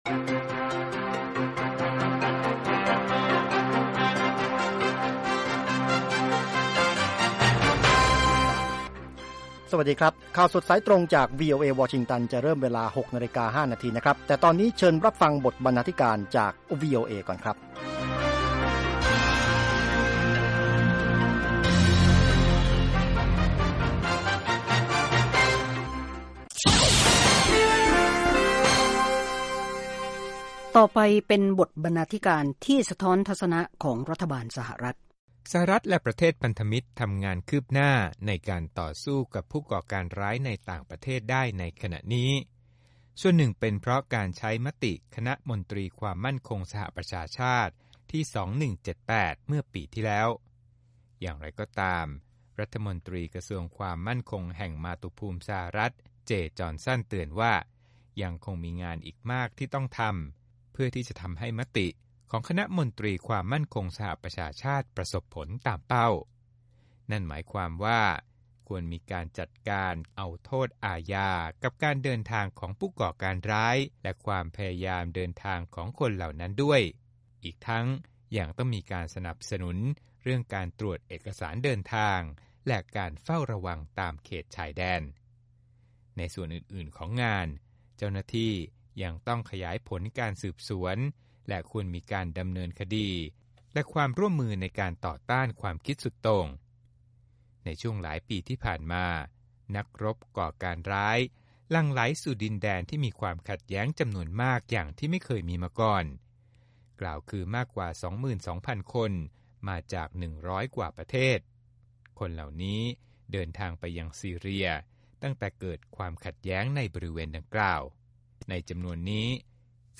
ข่าวสดสายตรงจากวีโอเอ ภาคภาษาไทย 6:00 – 6:30 น.วันพฤหัสบดี 11 มิ.ย. 2558